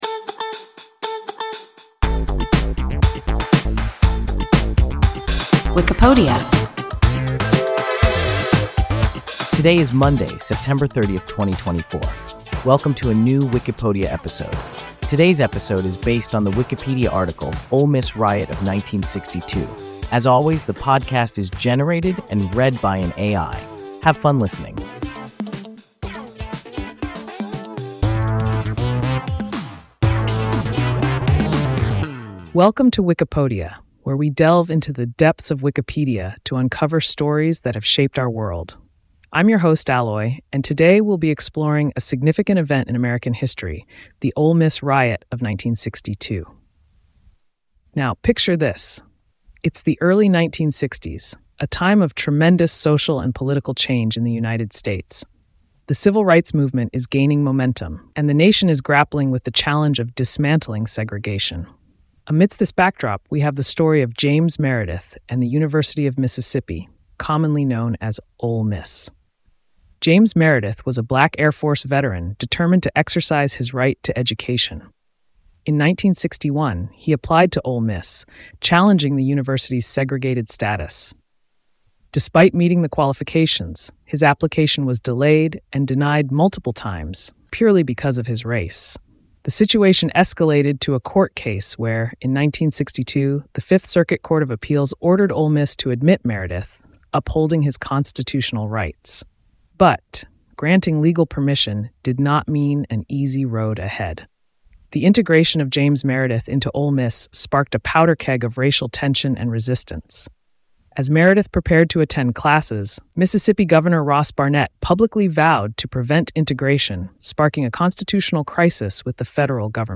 Ole Miss riot of 1962 – WIKIPODIA – ein KI Podcast